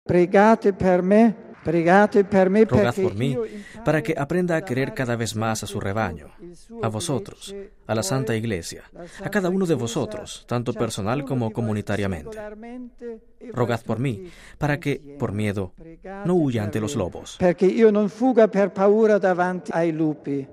Palabras de Benedicto XVI en la primera homilía de su Pontificado.